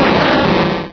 Cri de Foretress dans Pokémon Rubis et Saphir.